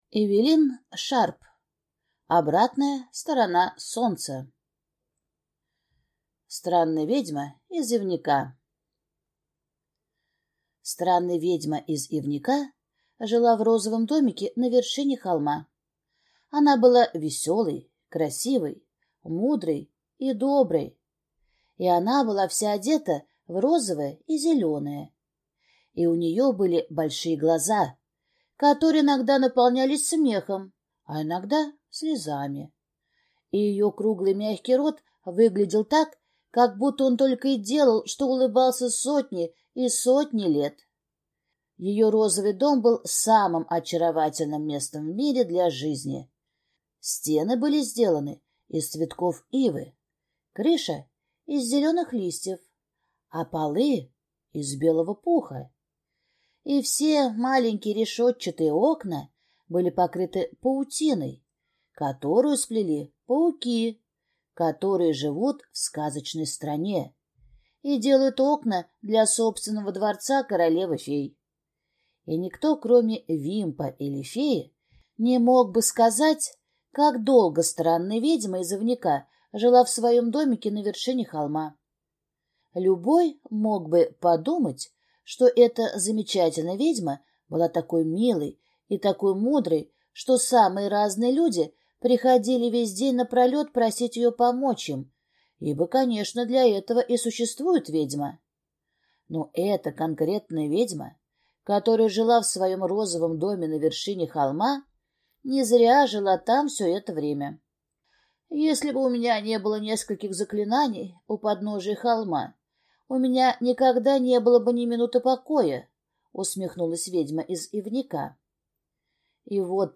Аудиокнига Обратная сторона солнца | Библиотека аудиокниг